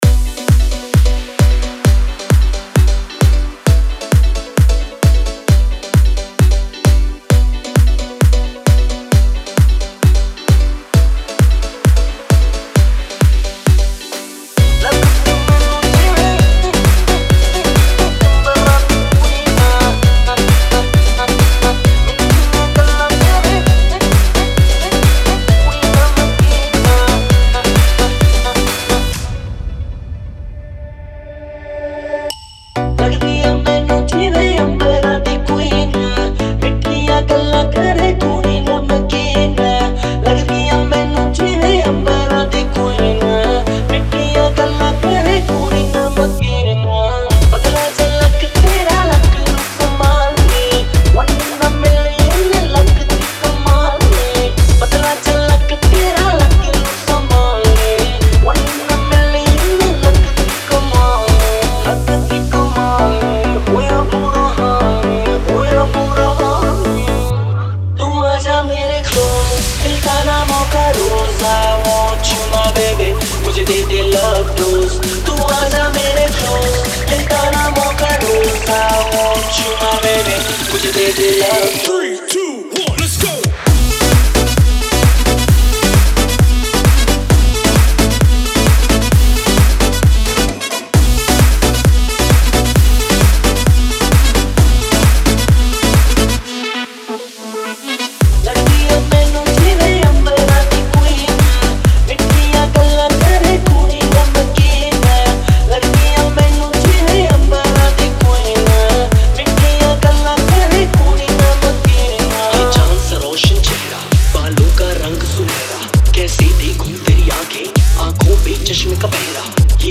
Bollywood Single Remixes